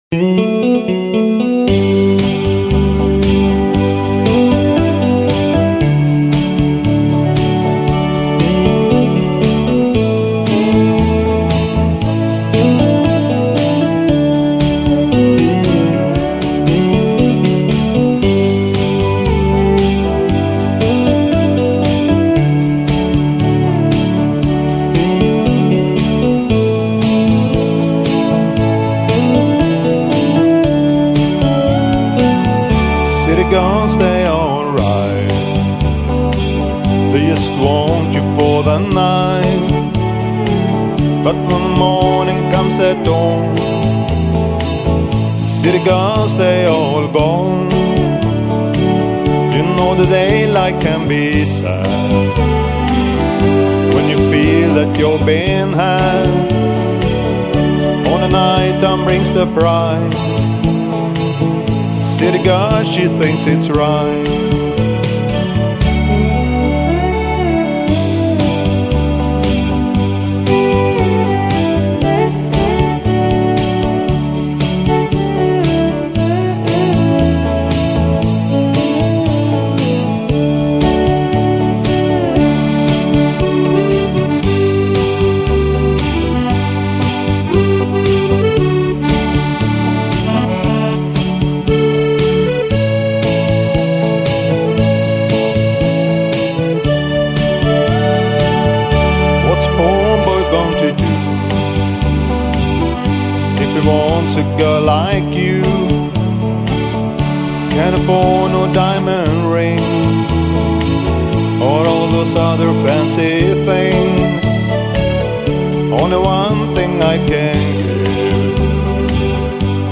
• Trubadur